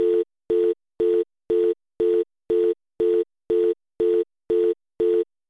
Call_Busy.9a74262a039f41f87fb1.mp3